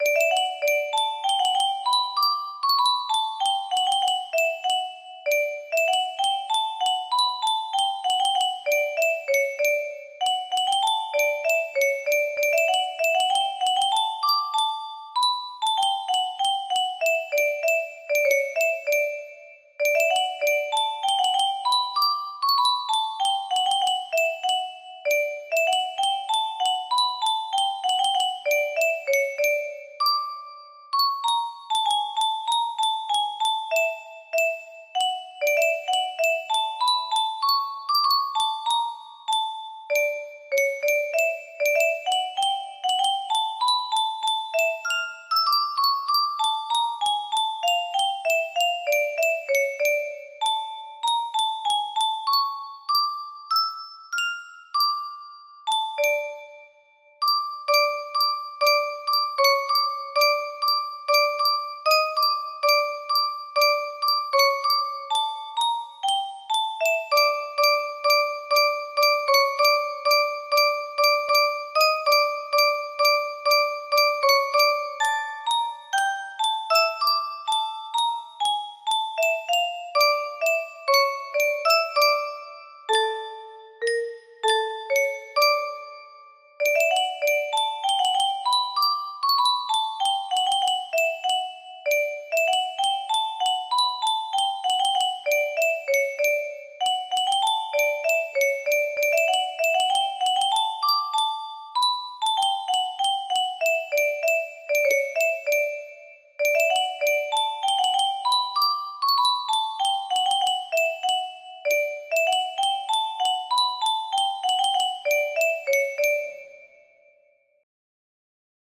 Grand Illusions 30 (F scale)
This time in F major.